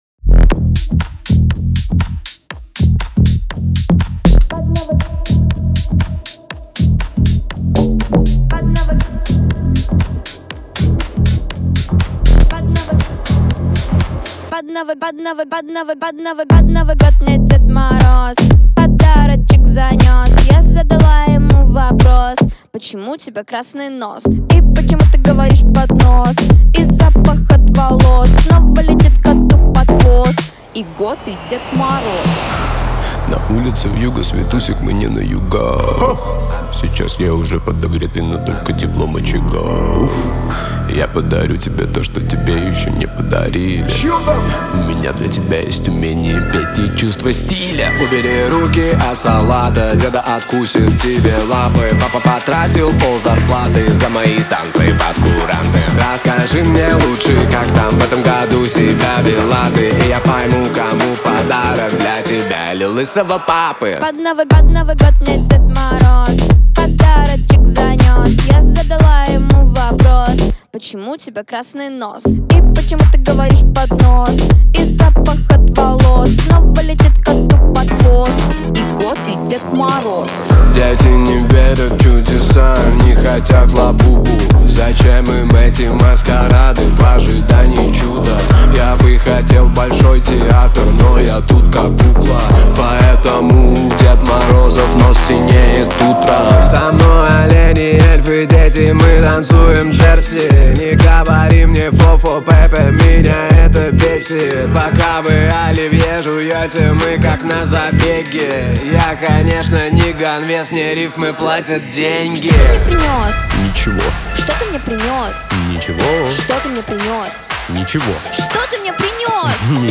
Новогодняя музыка